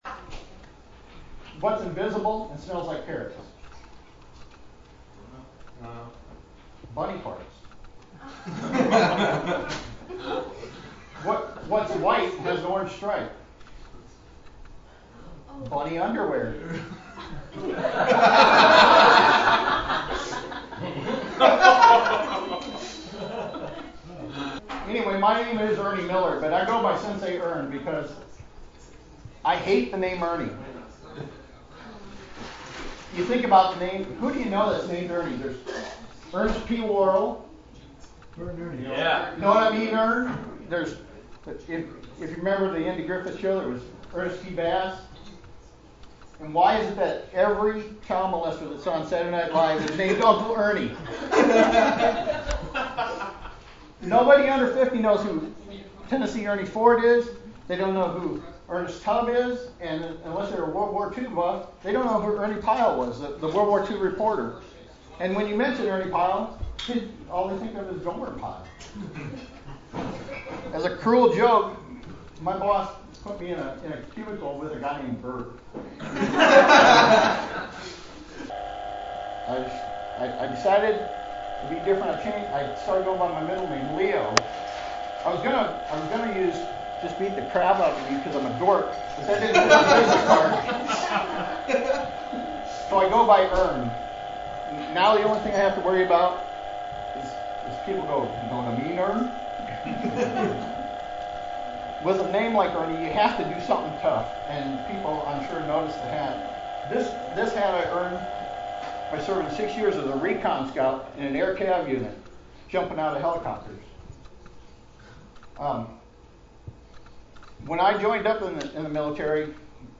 2004-10-02 Stand Up Performance